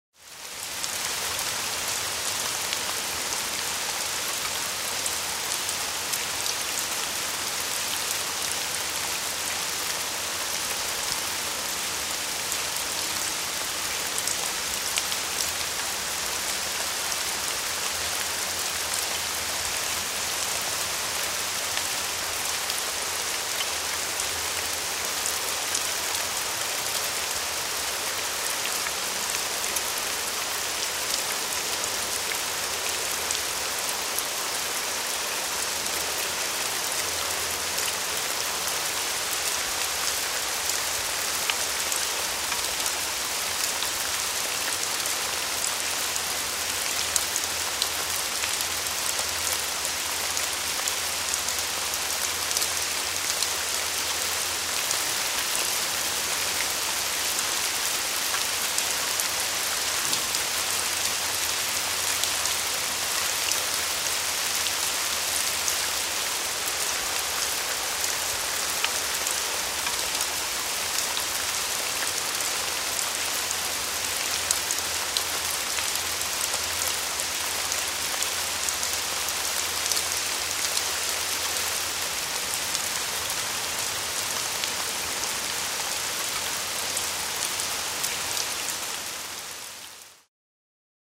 Дождь по асфальту и траве